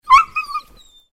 Звуки скулящей собаки
Звук, когда собаку обидели или причинили боль